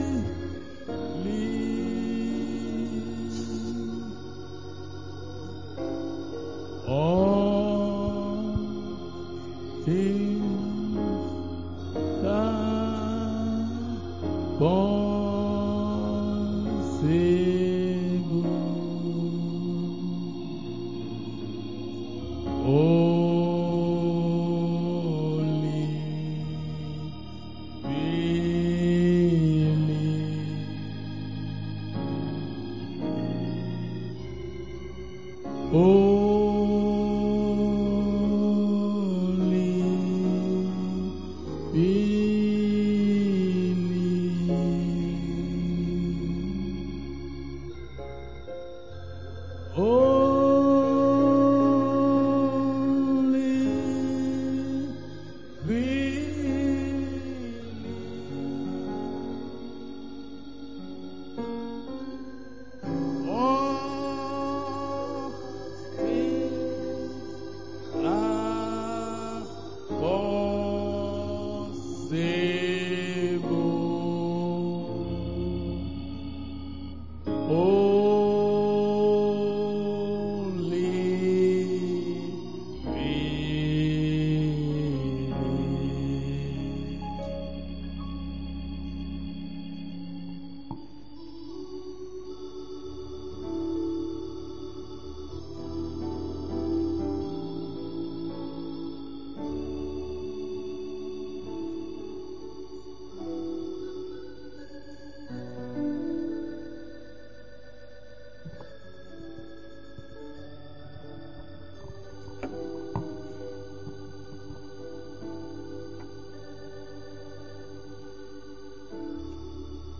Wed. Prayer Meeting 19-11-25